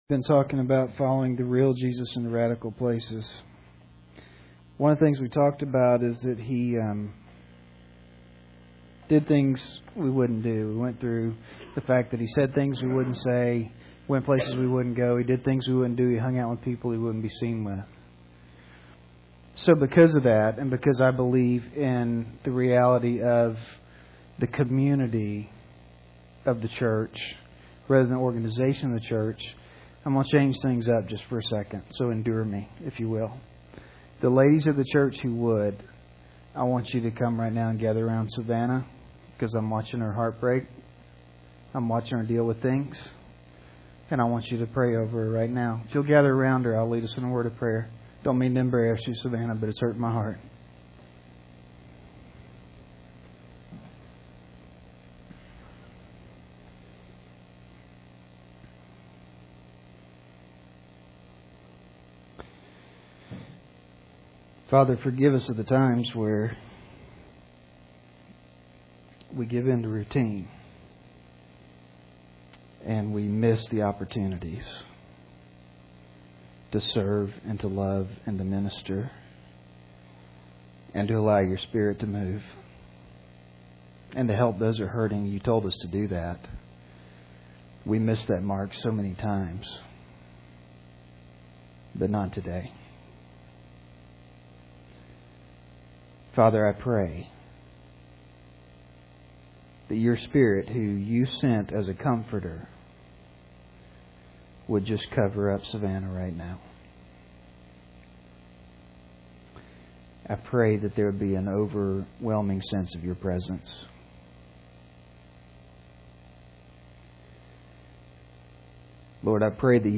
(Note: Technical difficulties interrupted this recording at 23:47. It resumes at 25:58.)